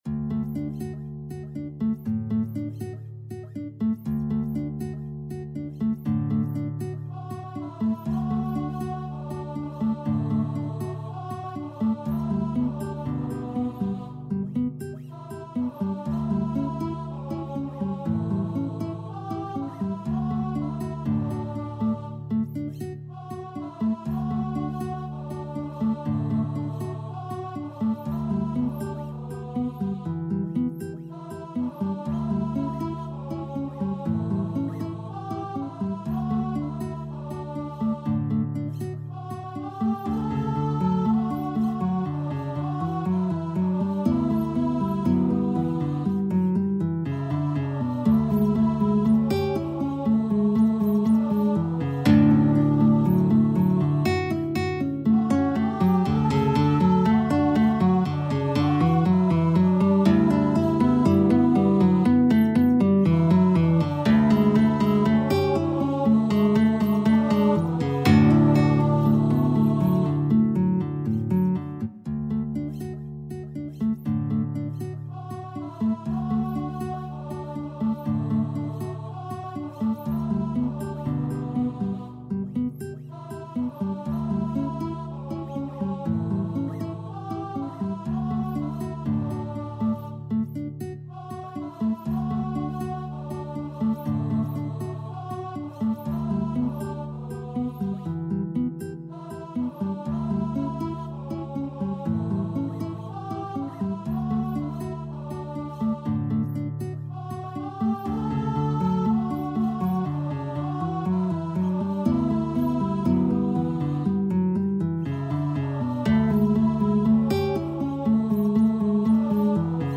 4/4 (View more 4/4 Music)
Adagio espressivo =c.60
Traditional (View more Traditional Guitar and Vocal Music)
world (View more world Guitar and Vocal Music)